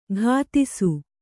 ♪ ghātisu